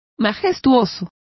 Complete with pronunciation of the translation of kingly.